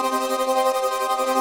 Index of /musicradar/shimmer-and-sparkle-samples/170bpm
SaS_MovingPad01_170-C.wav